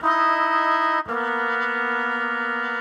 Index of /musicradar/gangster-sting-samples/85bpm Loops
GS_MuteHorn_85-EB.wav